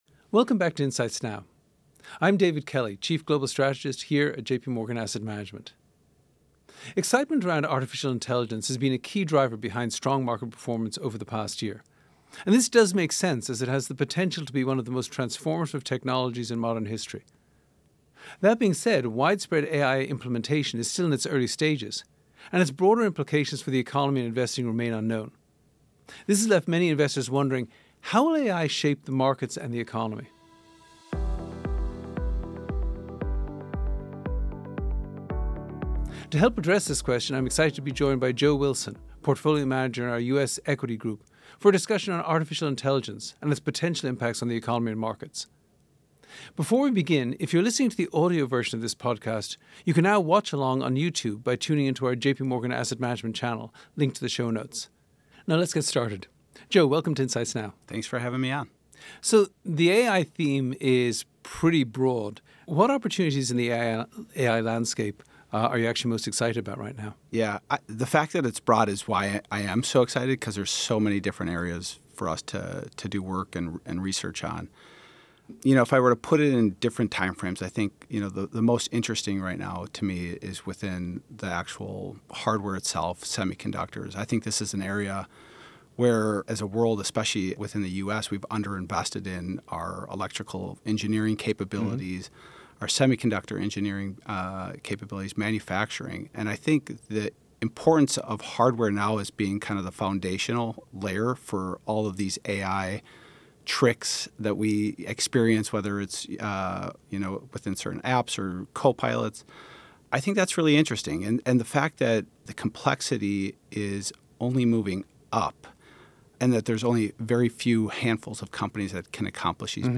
for a discussion on artificial intelligence and its potential impacts on the economy and markets.